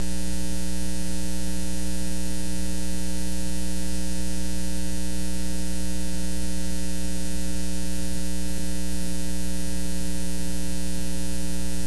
Tag: 128 bpm House Loops Synth Loops 1.26 MB wav Key : Unknown